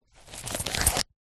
Звуки кошелька
Достаем монету из кошелька